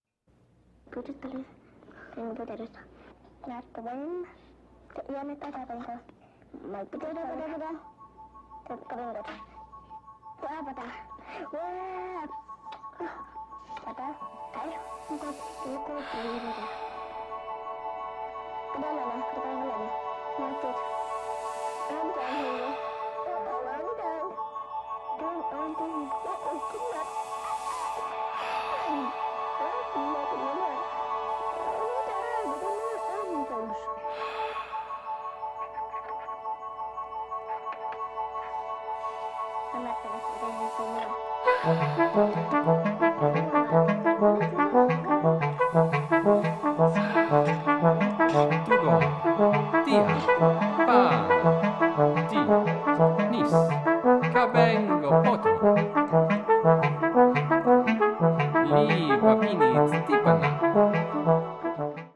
• Genres: Classical
baritone voice, bass clarinet, trombone, trumpet